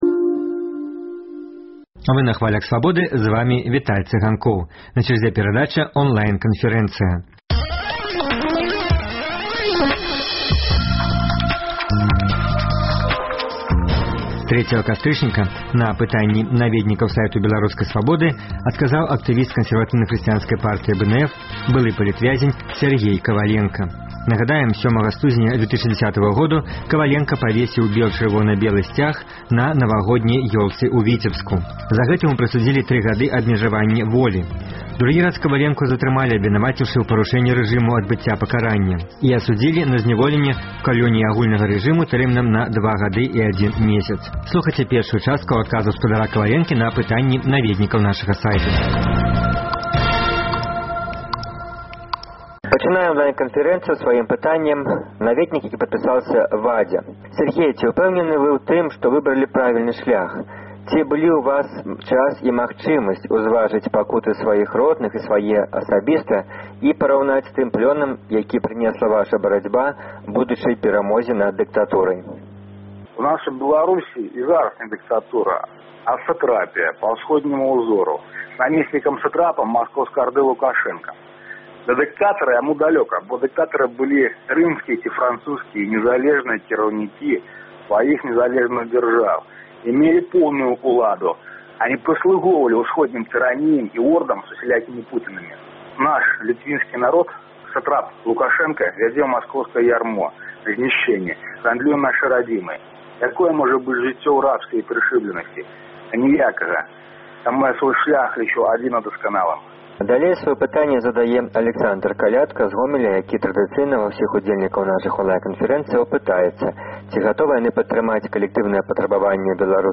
Онлайн-канфэрэнцыя